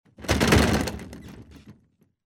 Звук землетрясения в доме — звуки тряски, падения, разрушения
Резко задрожал шкаф